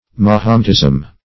Mahometism \Ma*hom"et*ism\, n.